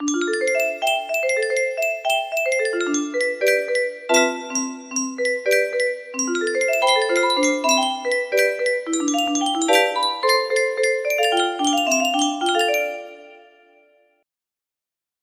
star test music box melody